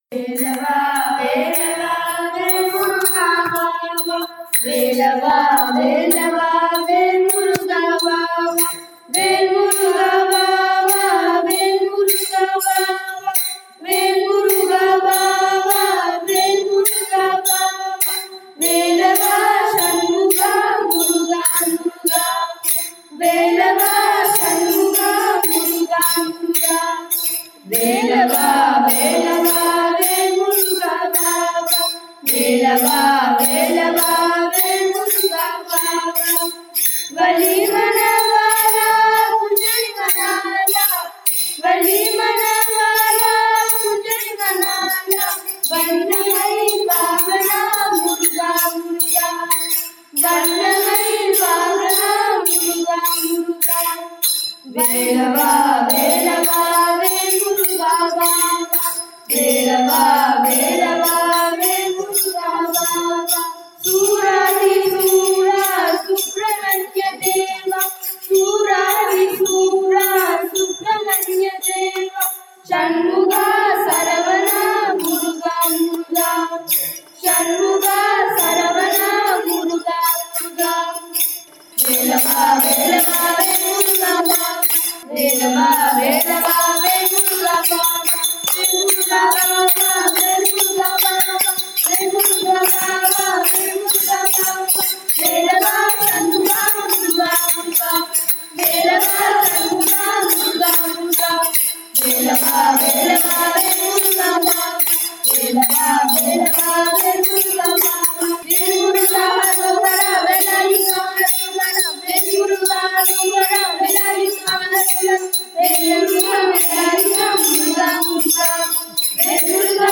பாடியவர்கள்:    சங்கீர்த்தன இயக்கம் குழந்தைகள்